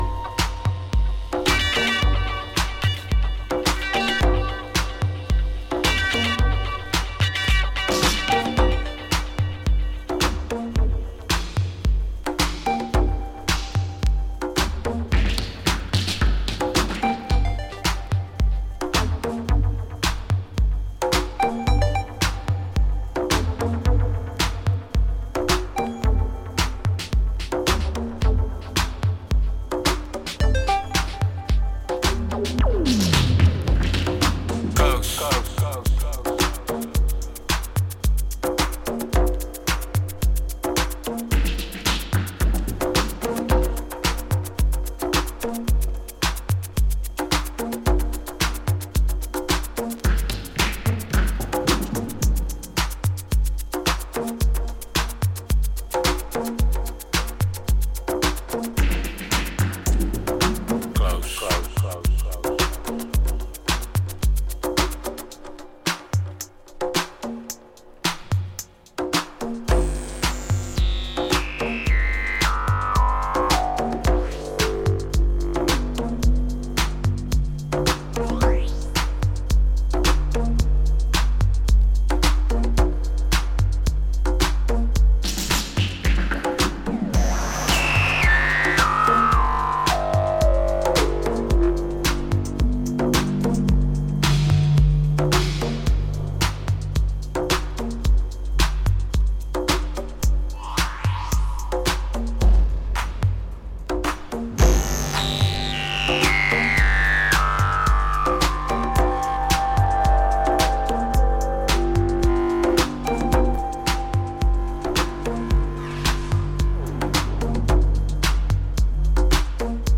原曲を程よくダブ処理を加えてトリッピーなエレクトロ・ダブに仕立ててみせた、これまた堪らない仕上がりとなっています。